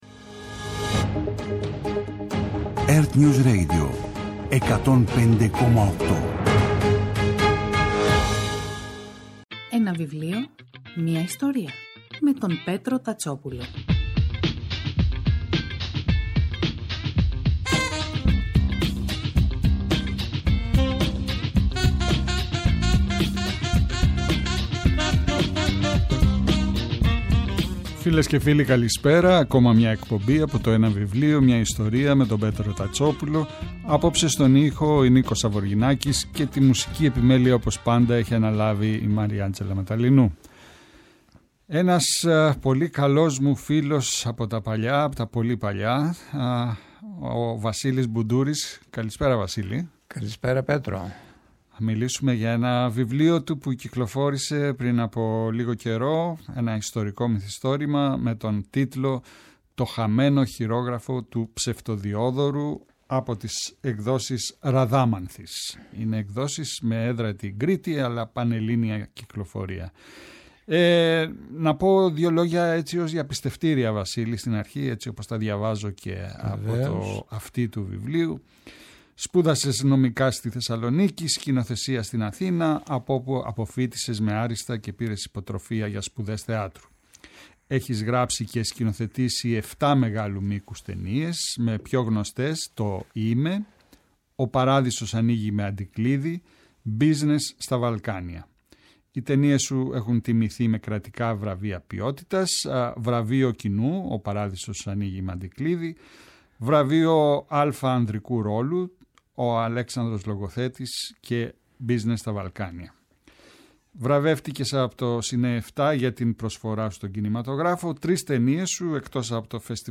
Κάθε Σάββατο και Κυριακή, στις 5 το απόγευμα στο ertnews radio της Ελληνικής Ραδιοφωνίας ο Πέτρος Τατσόπουλος, παρουσιάζει ένα συγγραφικό έργο, με έμφαση στην τρέχουσα εκδοτική παραγωγή, αλλά και παλαιότερες εκδόσεις. Η γκάμα των ειδών ευρύτατη, από μυθιστορήματα και ιστορικά μυθιστορήματα, μέχρι βιογραφίες, αυτοβιογραφίες και δοκίμια.